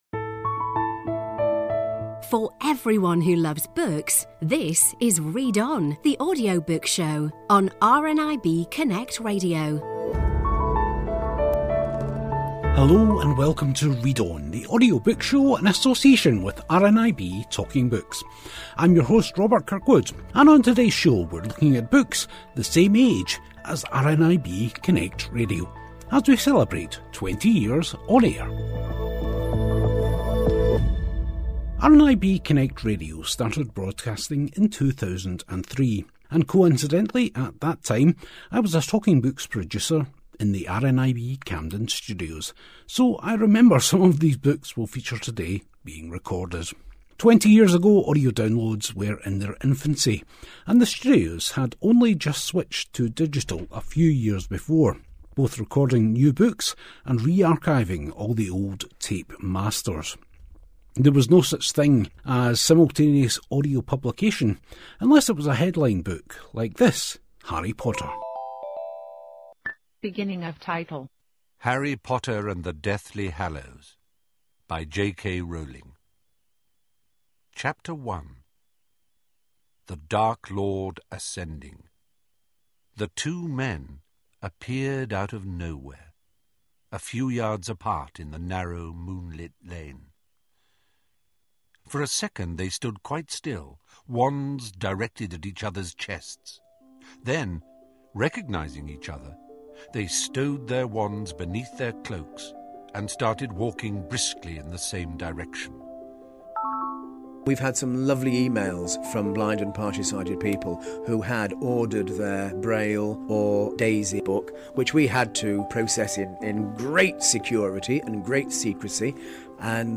and we chat to authors Lynne Truss (Eats, Shoots and Leaves), Mark Haddow (The Curious Incident of the Dog in the Night Time) and Bill Bryson (A Short History of Nearly Everything).